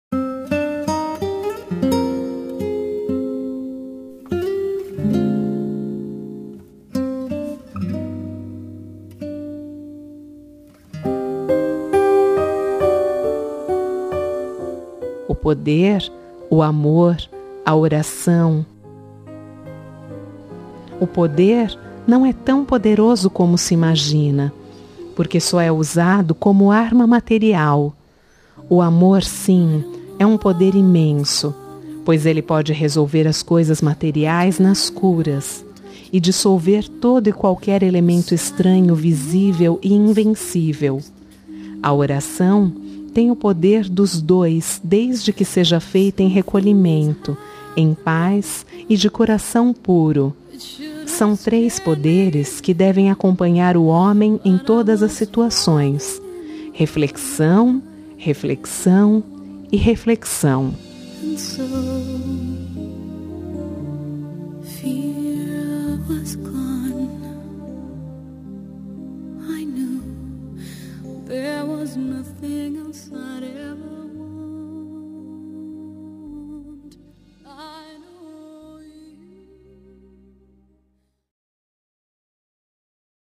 Telemensagem de Otimismo – Voz Feminina – Cód: 100105 – Oração